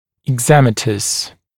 [ɪg’zemətəs][иг’зэмэтэс]экзематозный